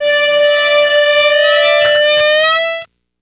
04 Alarmed E.wav